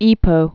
(ēpō)